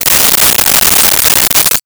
Key Movement 01.wav